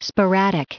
added pronounciation and merriam webster audio
683_sporadic.ogg